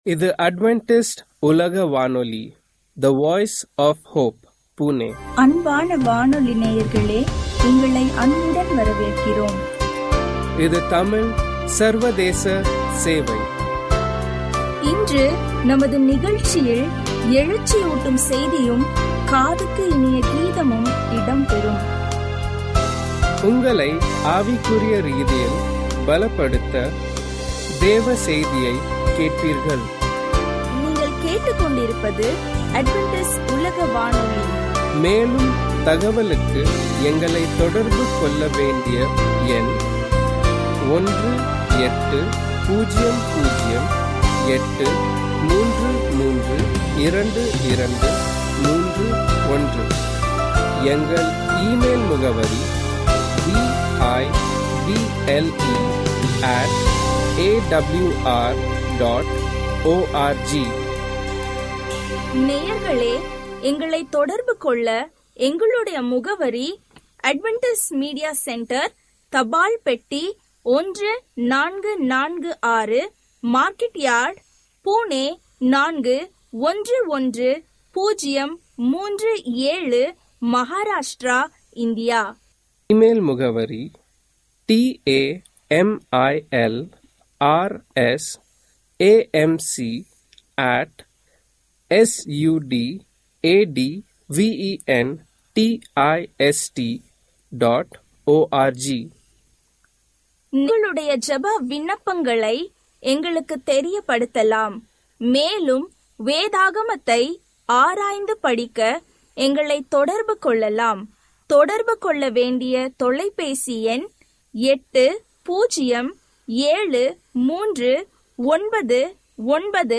Tamil radio program from Adventist World Radio